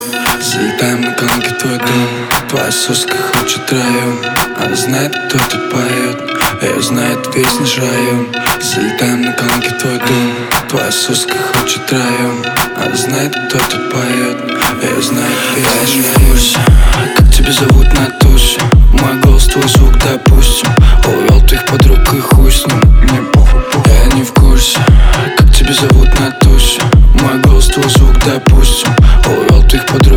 • Качество: 320, Stereo
громкие
русский рэп
house
грубые